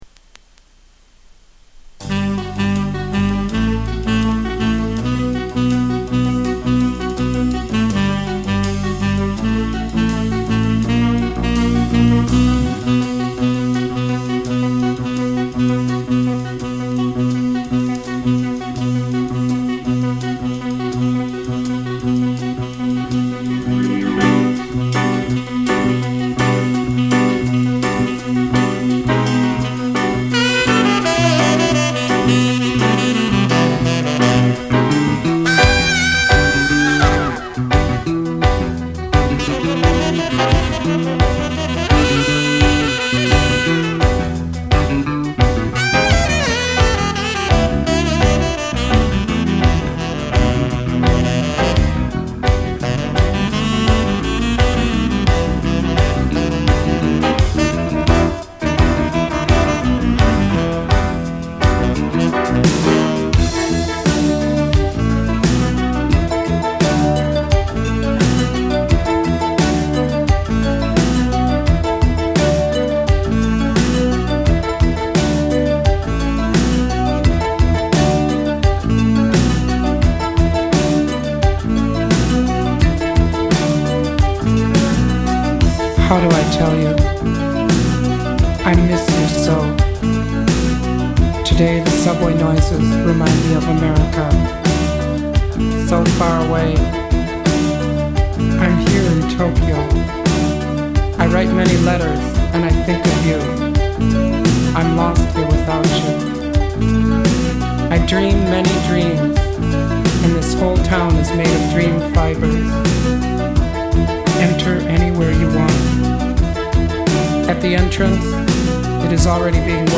Sax is great.  Keyboards, Bass, Vocals, its all so perfect.
Really soft + pretty vocals.